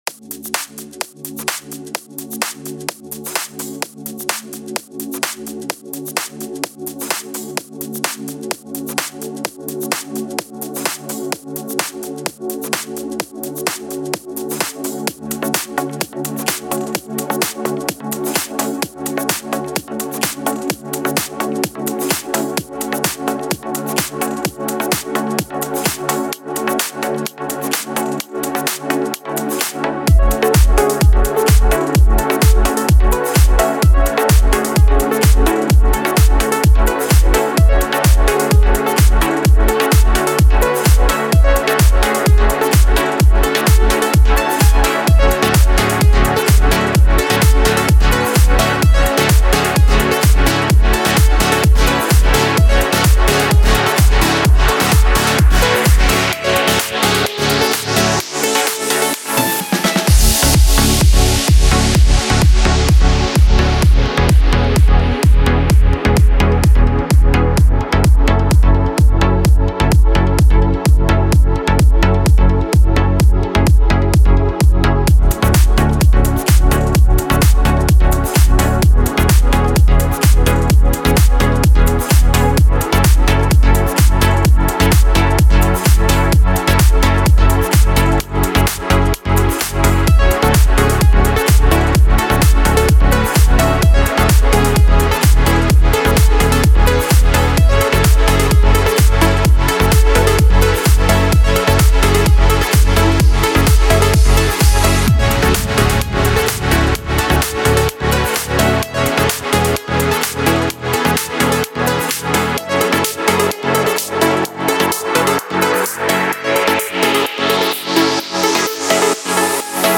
پراگرسیو هاوس
پر‌انرژی